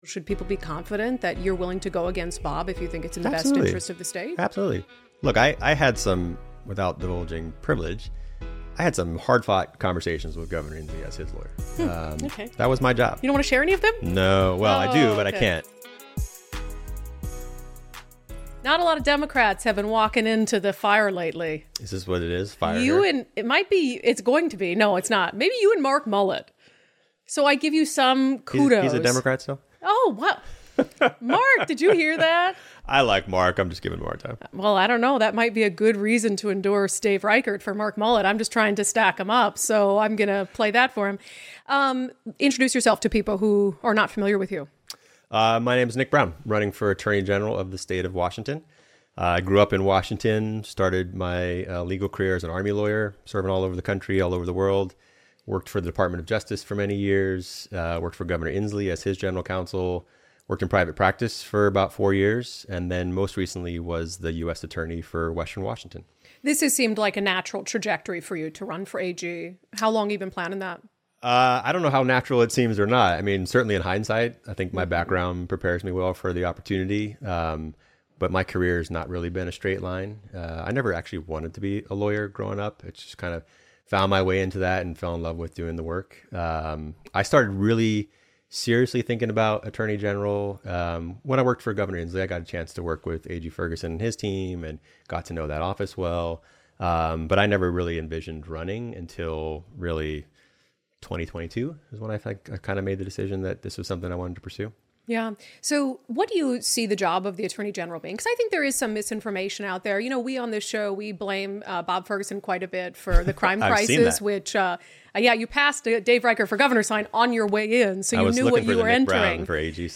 Democrat Nick Brown is running to become the state's chief law enforcer, but would he be more of the same? A former U.S. Attorney who also served as legal counsel for Governor Jay Inslee, Brown joined us to discuss everything from gun control to emergency powers to the prosecution of police officers.